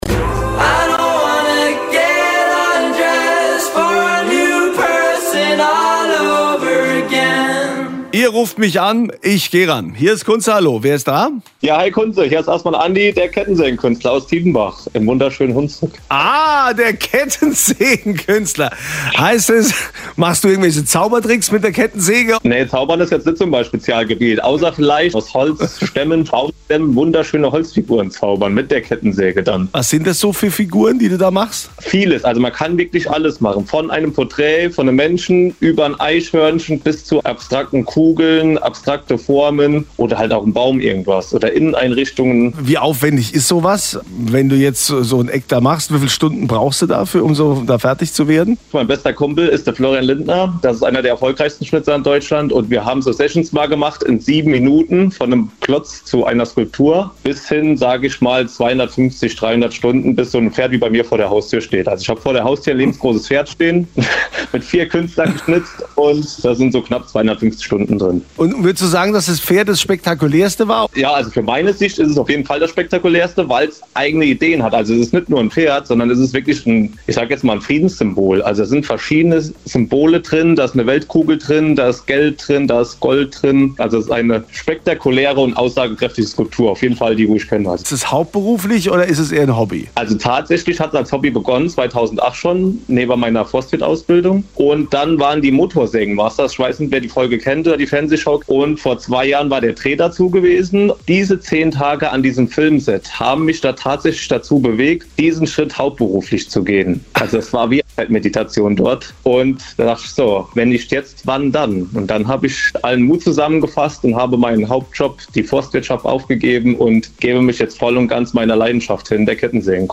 Ein Anruf von RPR1 Kunze Life im Radio. Dort durfte ich Auskunft über mein extravagantes Leben geben, was meine Berufung ist und wie ich mein Hobby zum Beruf gemacht habe.